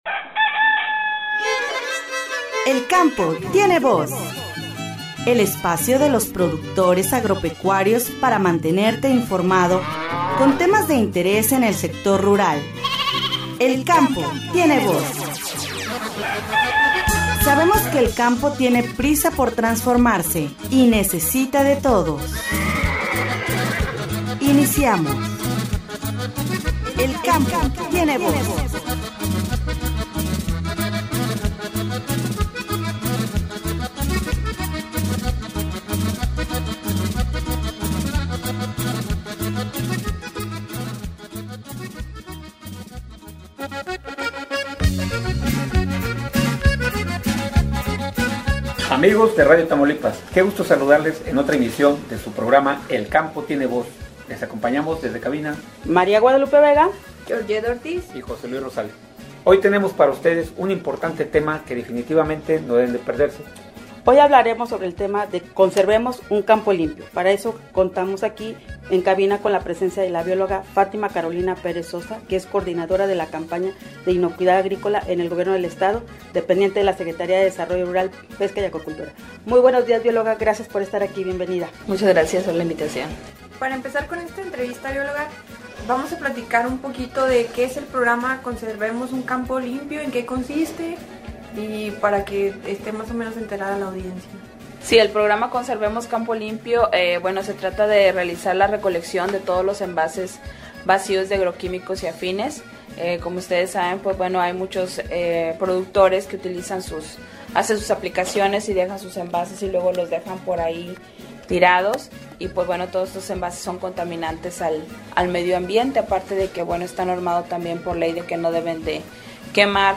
“El Campo Tiene Voz”, en esta ocasión se entrevistó a la Biol.